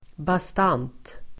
Uttal: [bast'an:t]